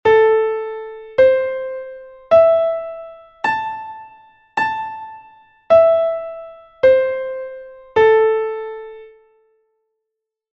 Imos escoitar estes audios con escalas e arpexios nos dous modos para que quede clara a diferencia, que se atopa na 3ª.